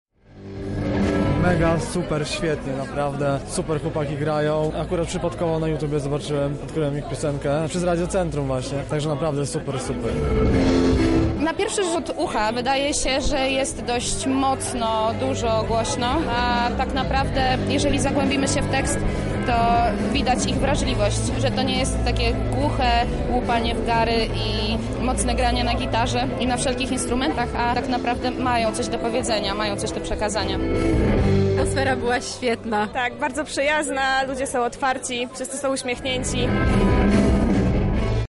To razem z nimi świętowaliśmy nasze 23. urodziny podczas Rockowej Sceny Radia Centrum.